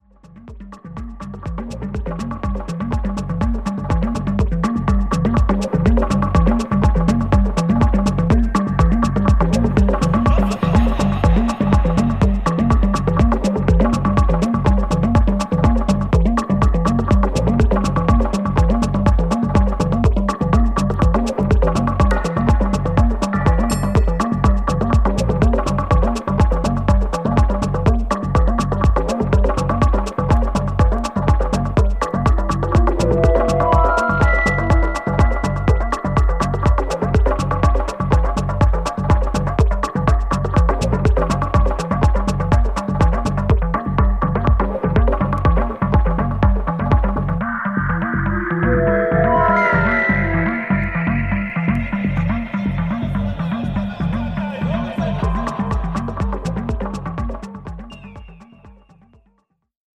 ホーム ｜ HOUSE / TECHNO > HOUSE
シンプルでミニマルなビートの中に中東らしいエスノ味やダスティな空気感が滲み出ているところが魅力的な1枚です。